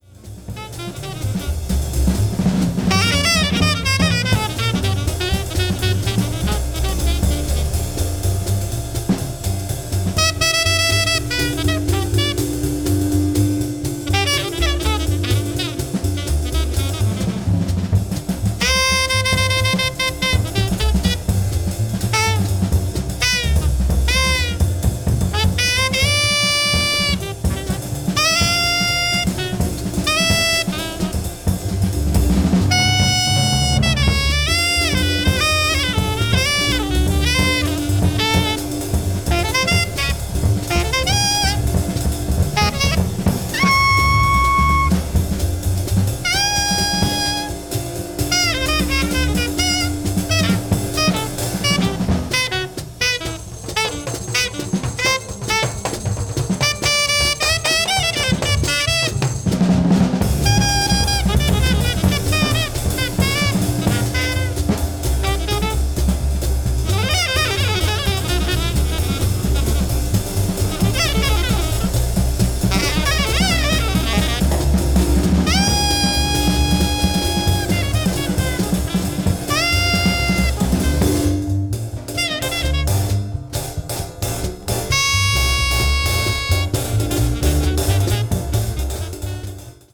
avant-jazz   free jazz   post bop